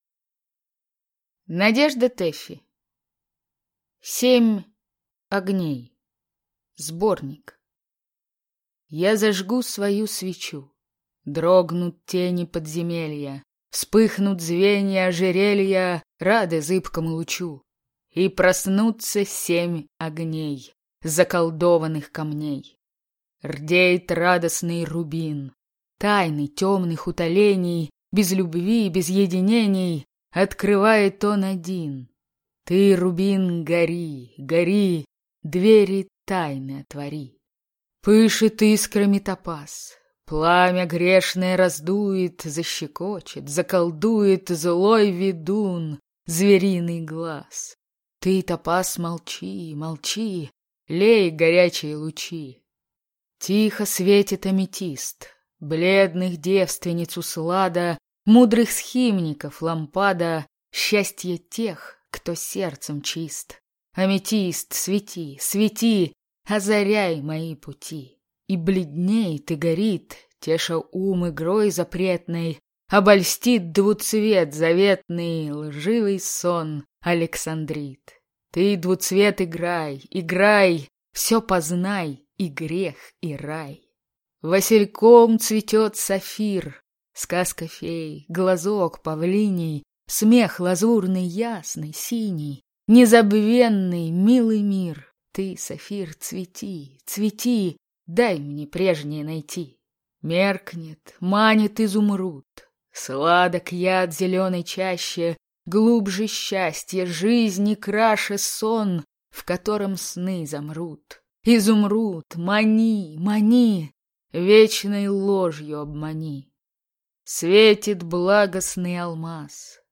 Аудиокнига Семь огней (сборник) | Библиотека аудиокниг